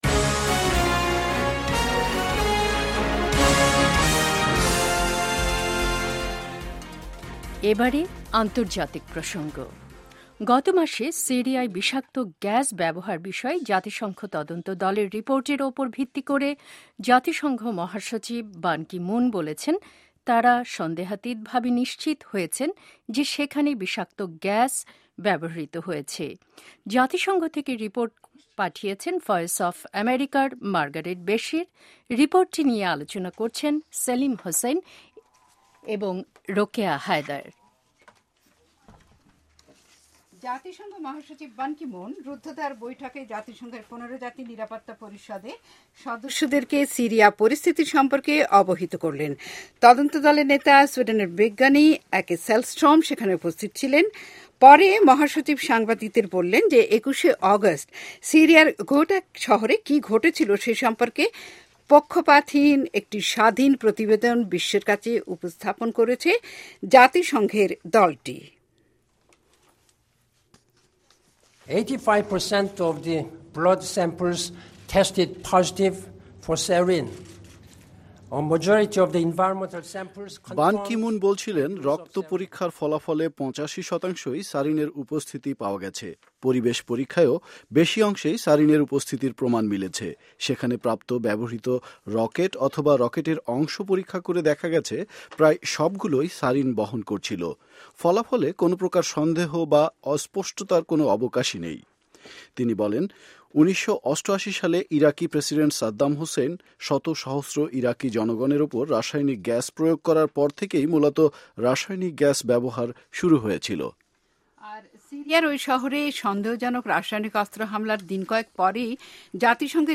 studio round table on syria